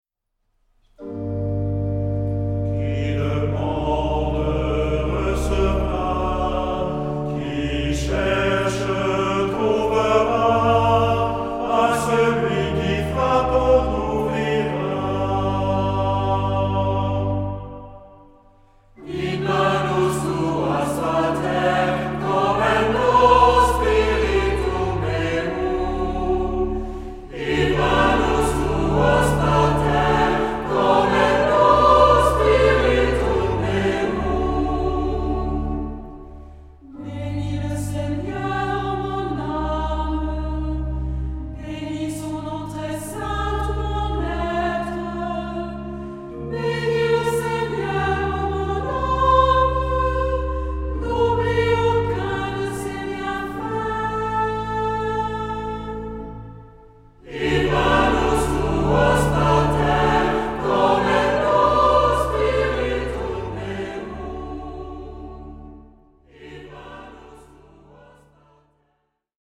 Genre-Stil-Form: Tropar ; Psalmodie
Charakter des Stückes: andächtig
Chorgattung: SAH  (3 gemischter Chor Stimmen )
Instrumente: Orgel (1) ; Melodieinstrument (ad lib)
Tonart(en): g-moll